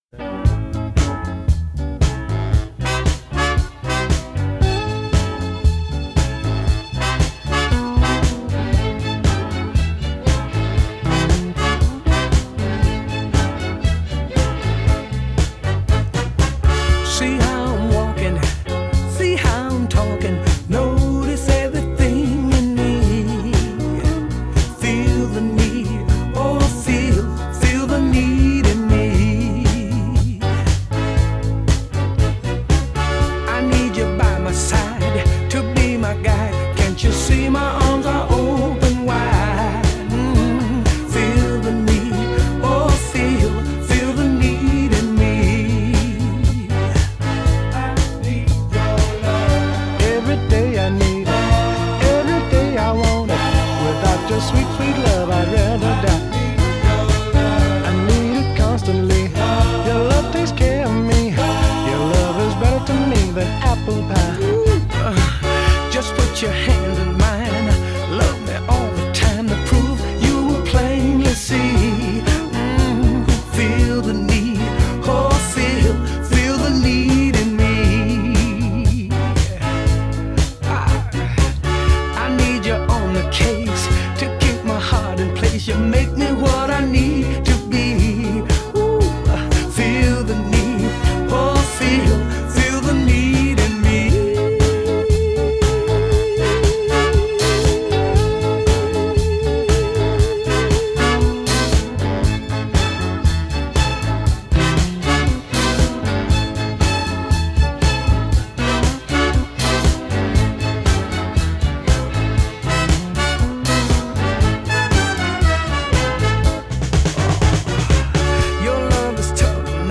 grupo vocal de R&B